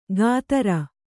♪ gātara